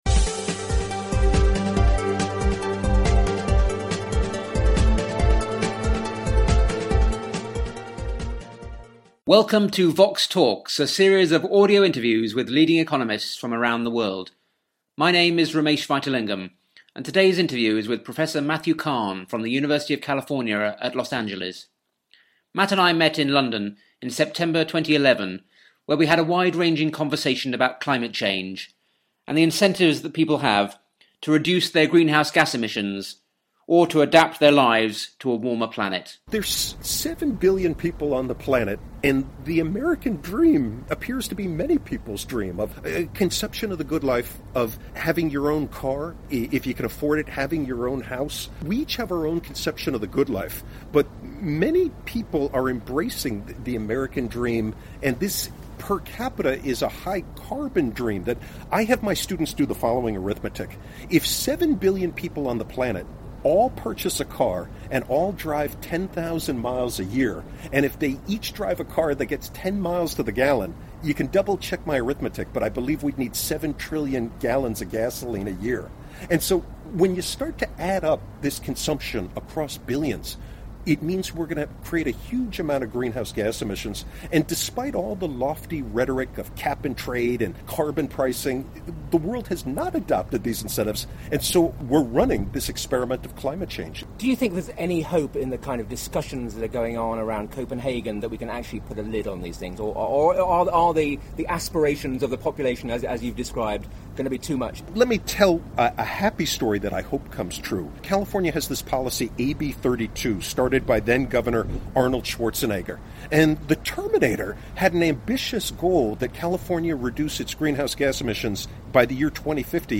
He explains how free market capitalism might drive effective climate change mitigation or adaptation. The interview was recorded at Growth Week in London in September 2011.